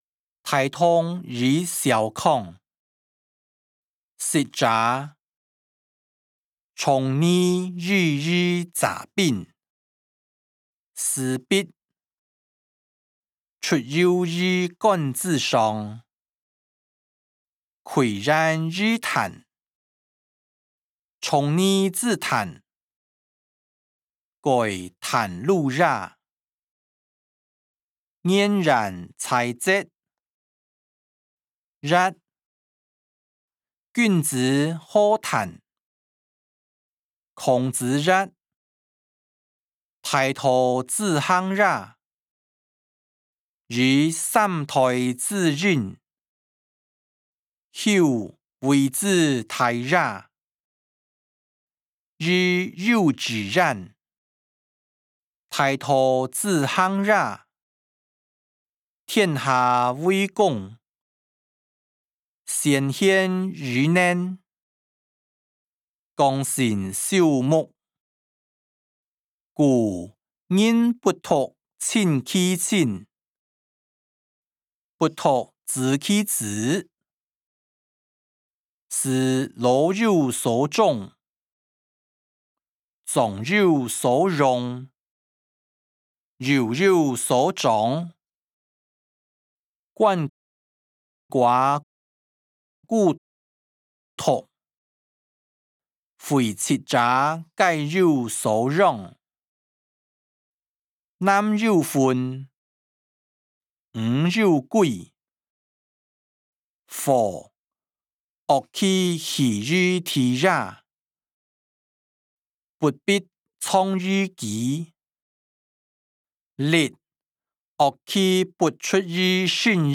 經學、論孟-大同與小康音檔(海陸腔)